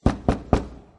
knock